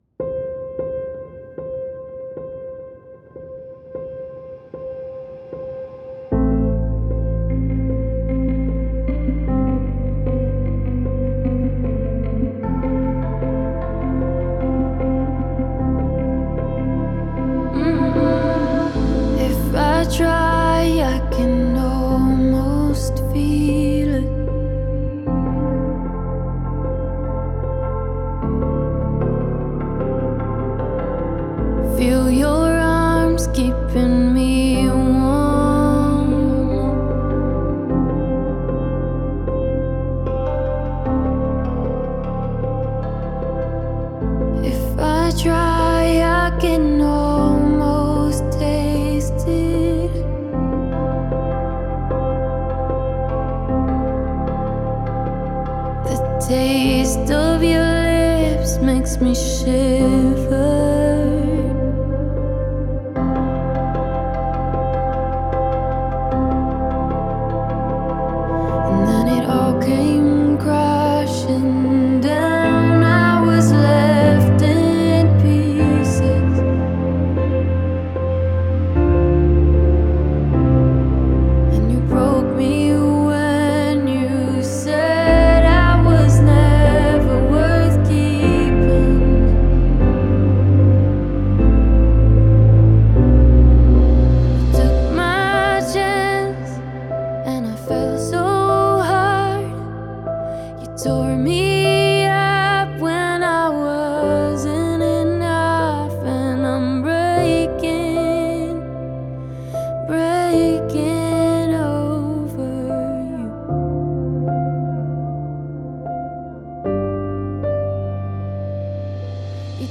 Emotive modern pop ballads.